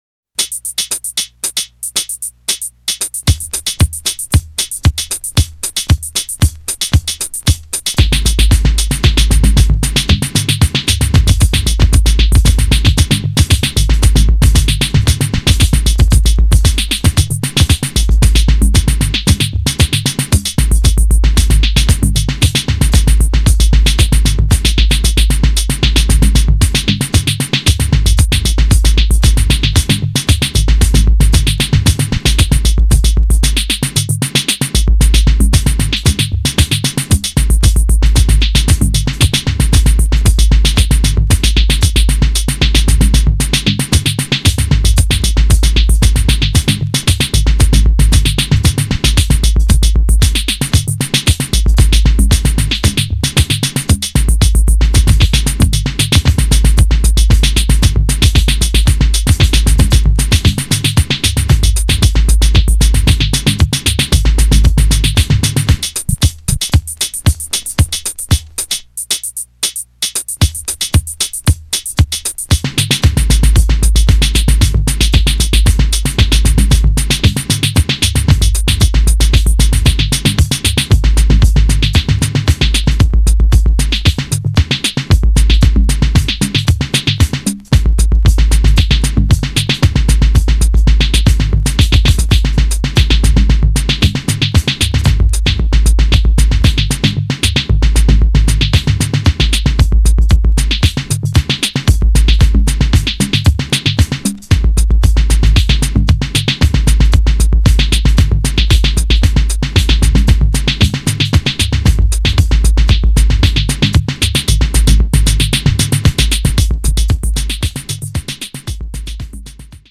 またもやダークな渦に飲み込まれます。